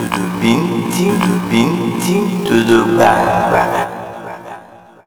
Index of /90_sSampleCDs/Zero-G - Total Drum Bass/Instruments - 3/track64 (Vox EFX)
04-Du Da Du.wav